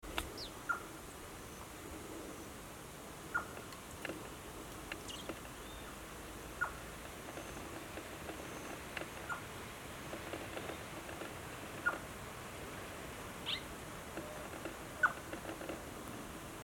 Crested Gallito (Rhinocrypta lanceolata)
Life Stage: Adult
Location or protected area: Parque Nacional Lihué Calel
Condition: Wild
Certainty: Photographed, Recorded vocal
MVI_9763-gallito-copeton.mp3